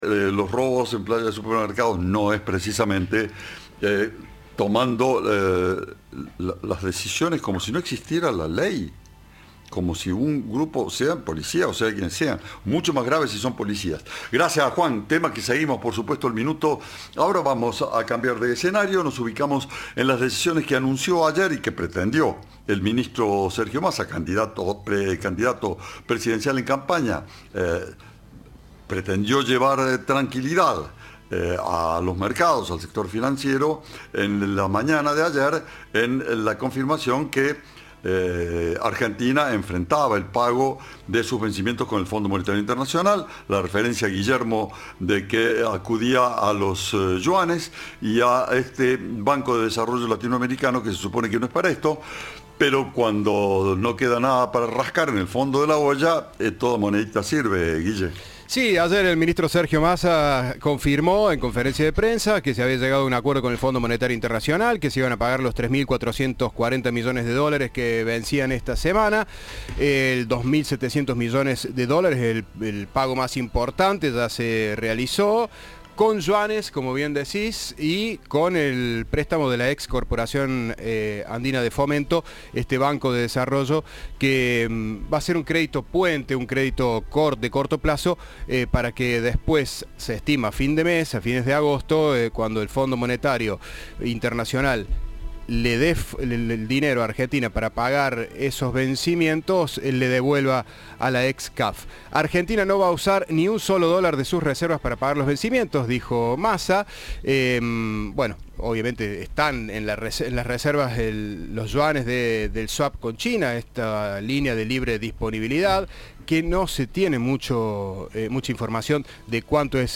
En diálogo con Cadena 3 se refirió al acuerdo que llegó el ministro con el FMI y dijo que habrá "más emisión monetaria y más endeudamiento del Tesoro".